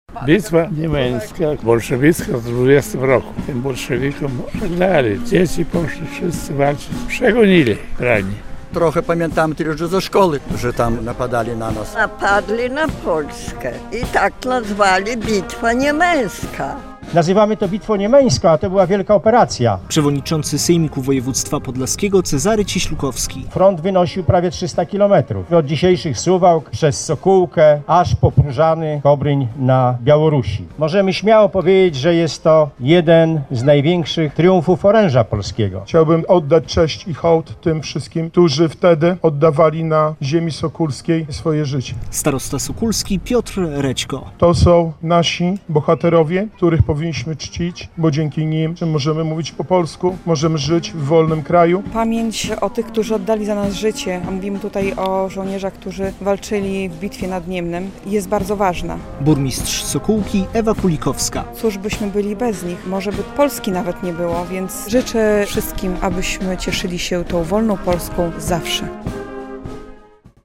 Wspólnie pomodlili się, odśpiewali hymn i wzięli udział w apelu pamięci. Władze Sokółki i powiatu sokólskiego, ale też jego mieszkańcy oddali hołd żołnierzom, którzy stracili życie w tzw. Bitwie Niemeńskiej.